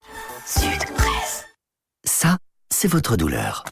Voici un extrait tel quel (donc non trafiqué) de la page de publicités diffusée le jeudi 22 mars 2018 à 18h sur la radio RTBF La Première (émission Soir Première).